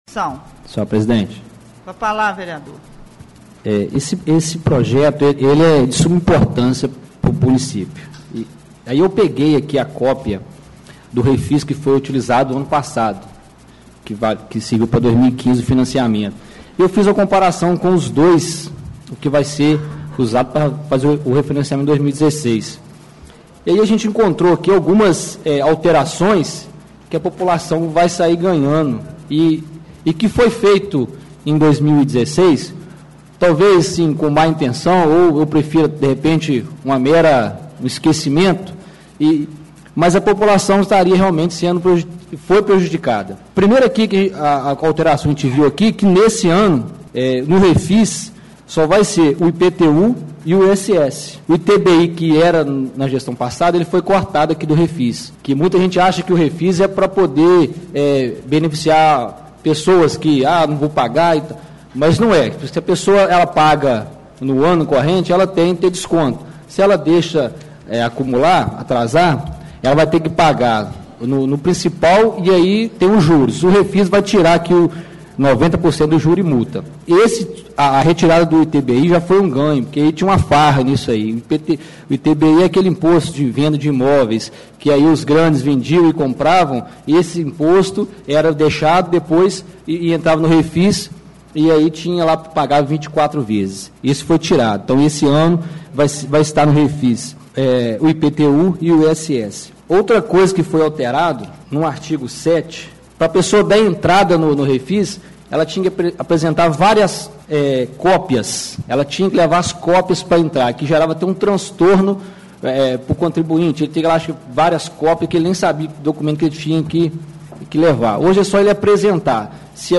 Leia o que foi dito naquela ocasião pelos vereadores Jorge da Kombi e José Roberto.